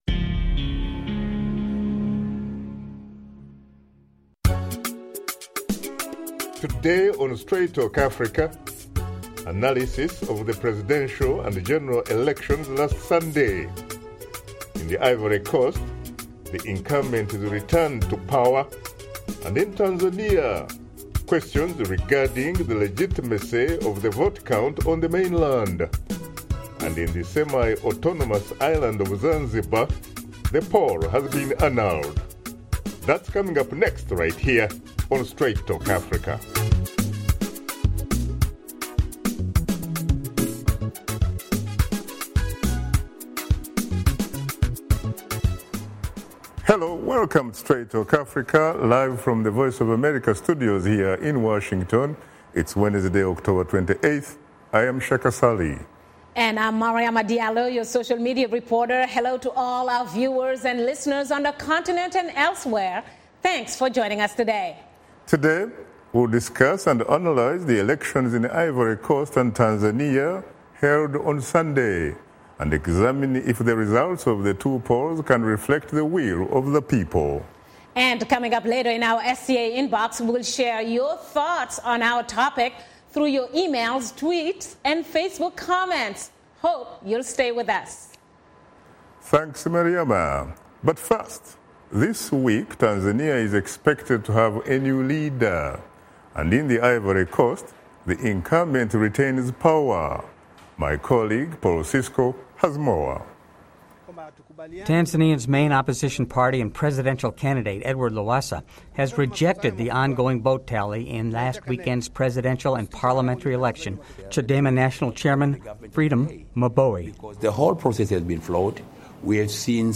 via Phone
via SKYPE